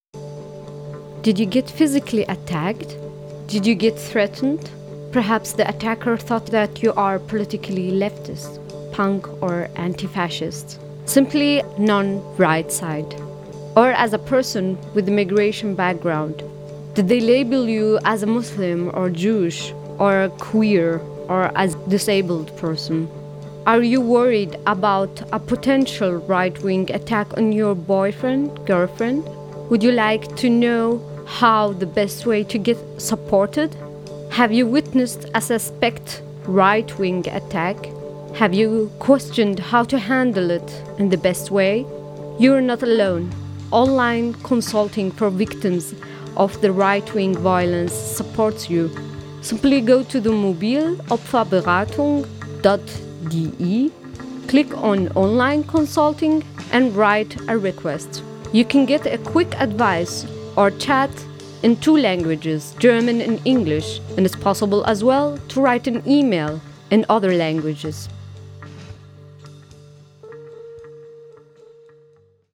jingle_promo_opferberatung_englisch.WAV